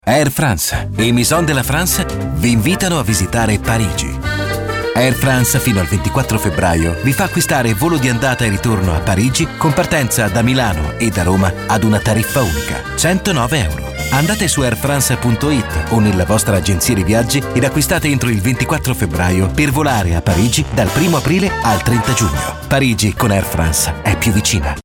Commercial 2